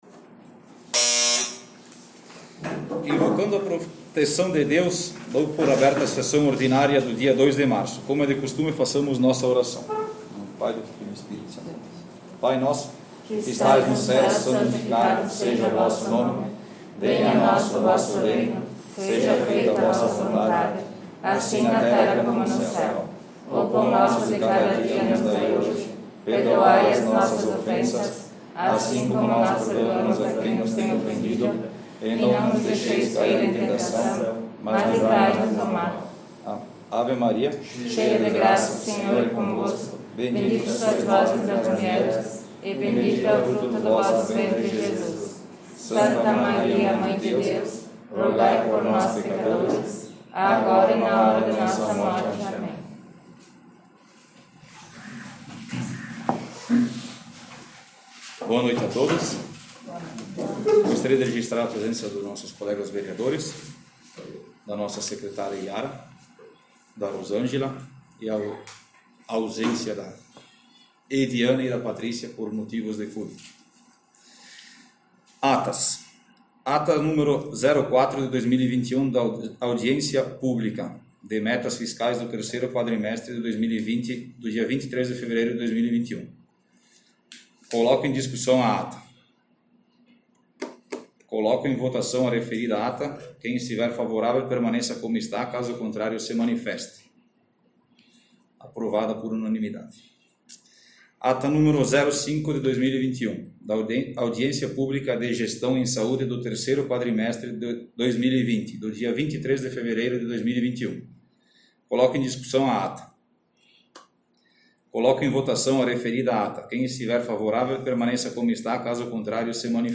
Sessão Ordinária 02/03/2021